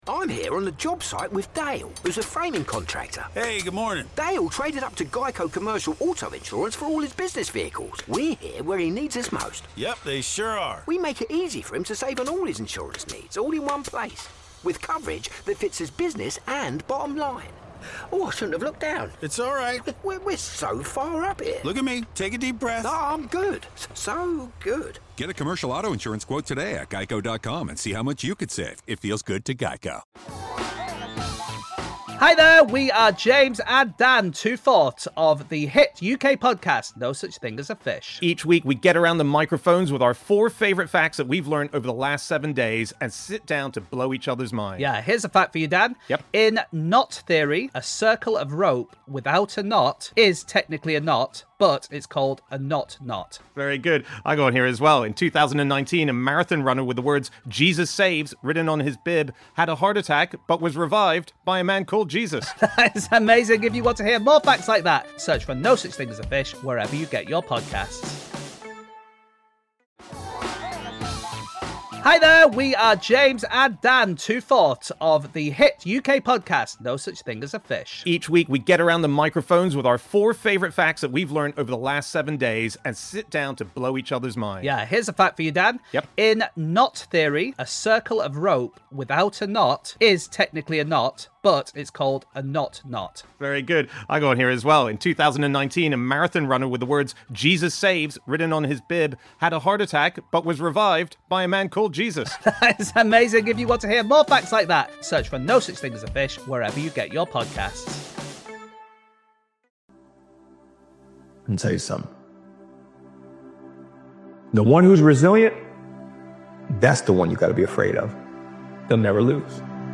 JUST RESET, RESTART AND REFOCUS AGAIN - Powerful Motivational Speech is a powerful and uplifting motivational speech created and edited by Daily Motivations. This motivational speech compilation focuses on the truth that setbacks are not the end-they are opportunities to rebuild discipline, regain consistency, and create unstoppable momentum.